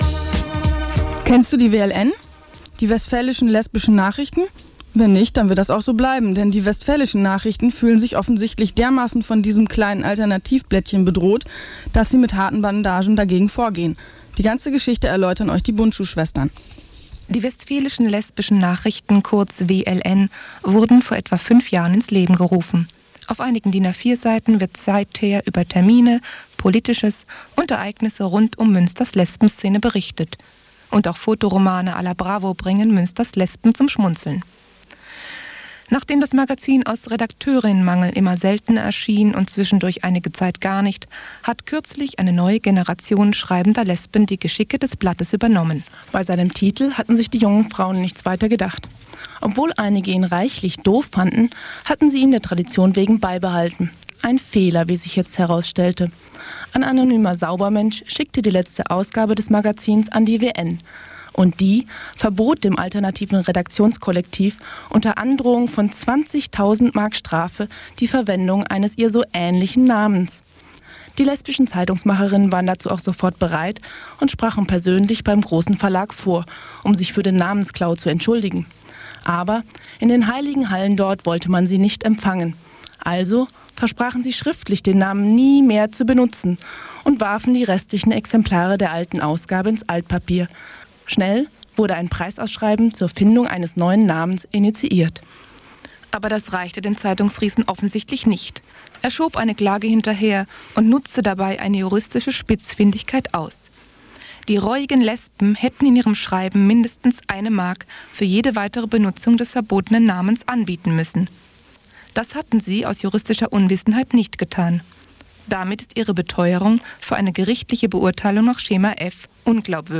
Um die Auseinandersetzungen zwischen der WN und der WLN ging es in einem Bericht des Bürgerfunk-Radios DonnaWettert. Diese Frauen-Radio-Sendung äußerte sich regelmäßig auch zu queeren Themen in Münster und darüber hinaus. Die Sendungen liefen zwischen 1992 und 2002 bei Antenne Münster.